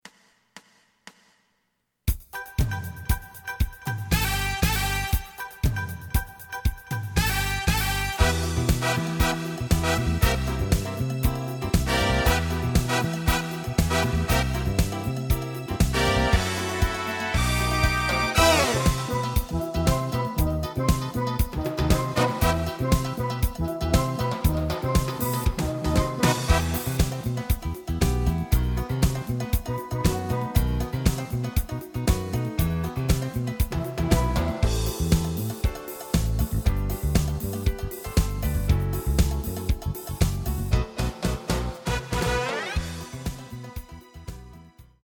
フルコーラス(カラオケ)